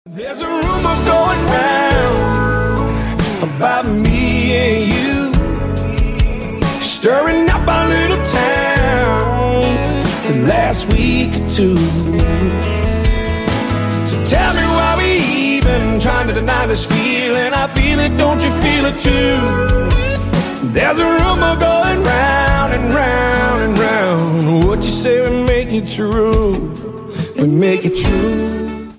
country music tones